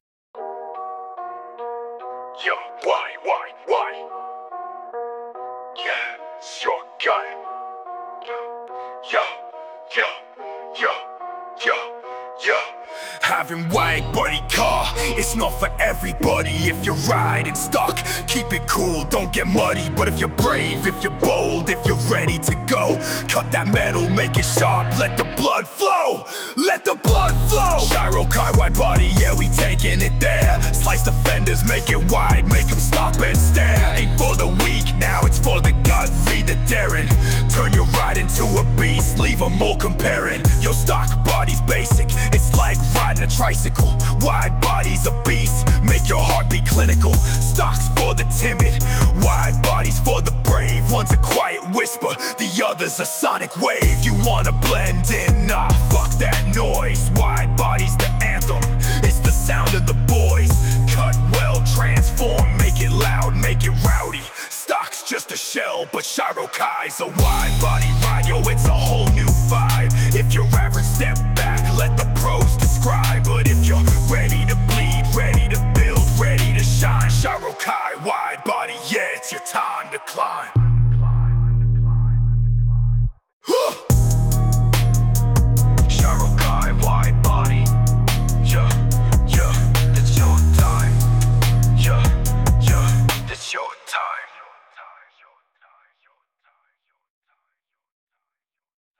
UK Drill/ RAP